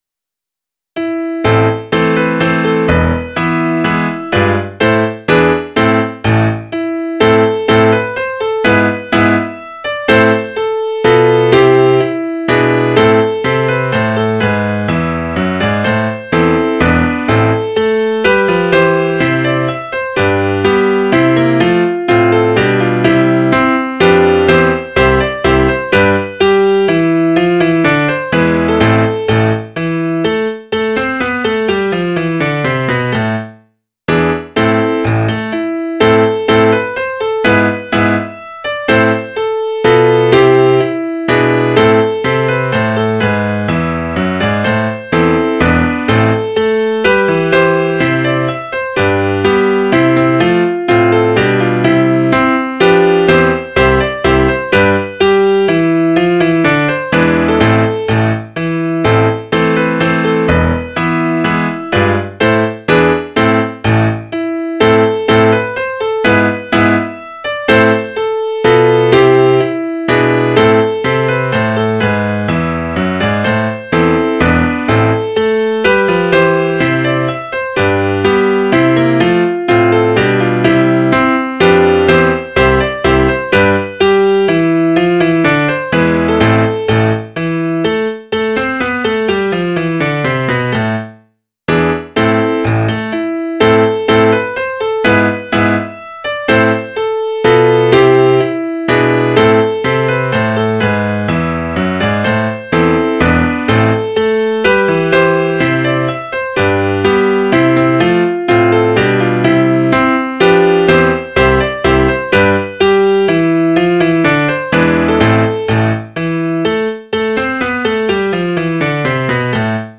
avenge.mid.ogg